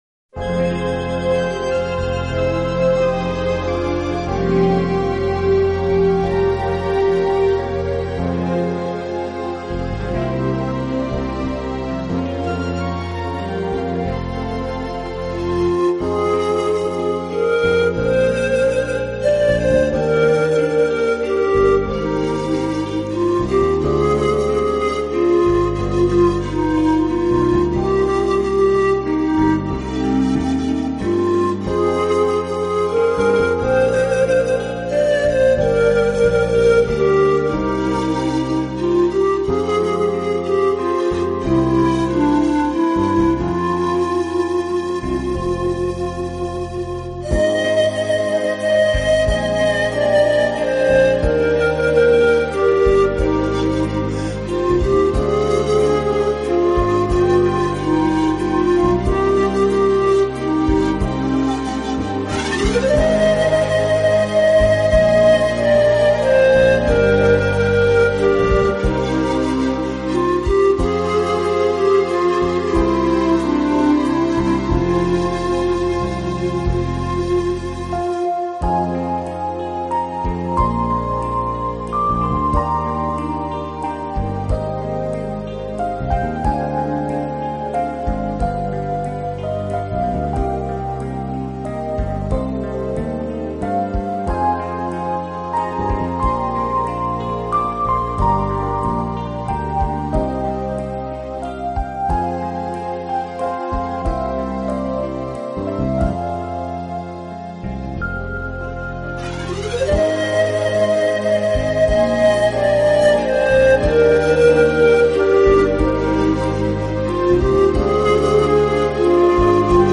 音乐类型：Instrumental